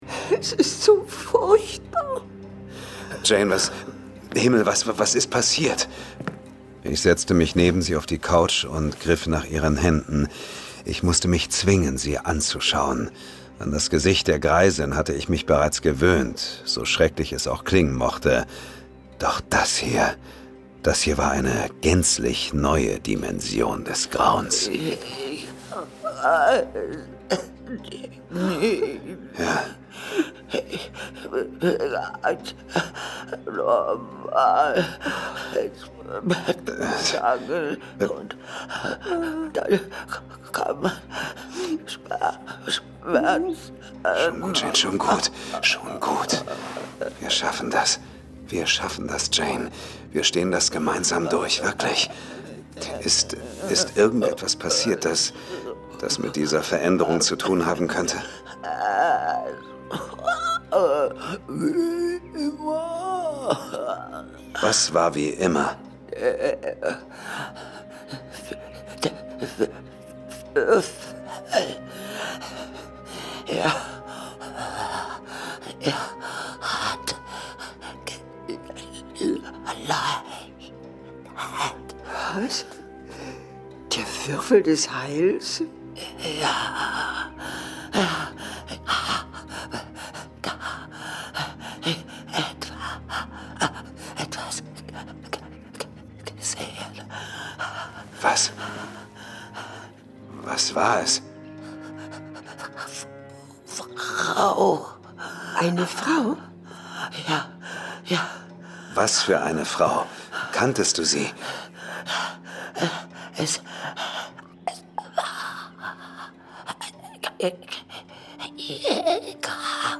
John Sinclair - Folge 187 Die Wölfin von Rom. Hörspiel. Jason Dark (Autor) Dietmar Wunder , diverse (Sprecher) Audio-CD 2025 | 1.